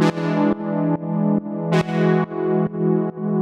GnS_Pad-dbx1:4_140-E.wav